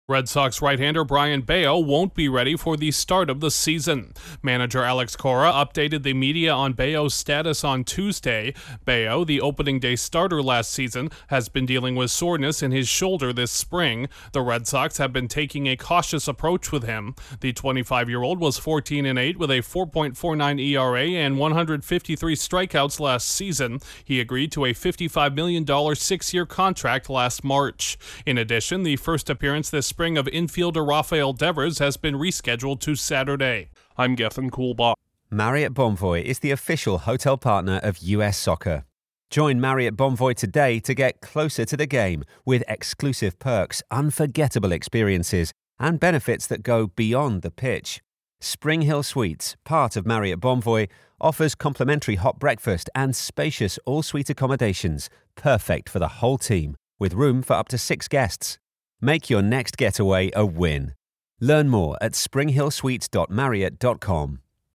A hopeful AL East contender could be without a pair of key players entering the new season. Correspondent